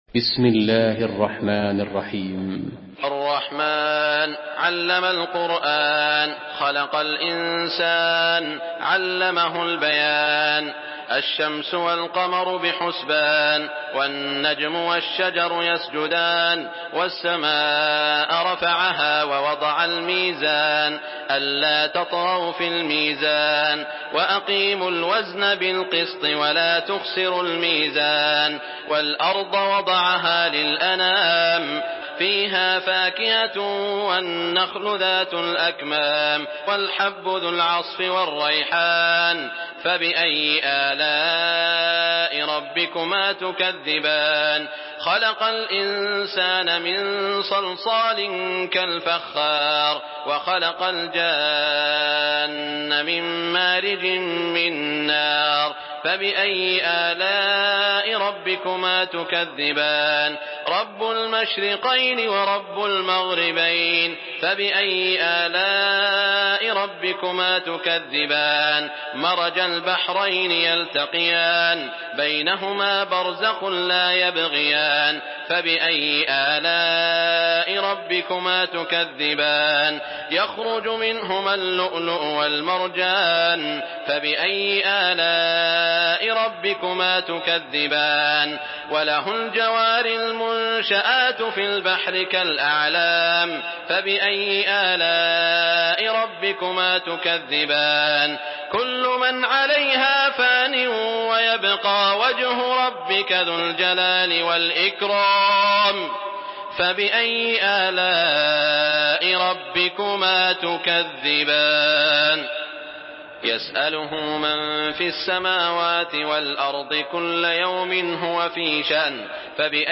Surah Ar-Rahman MP3 in the Voice of Saud Al Shuraim in Hafs Narration
Murattal Hafs An Asim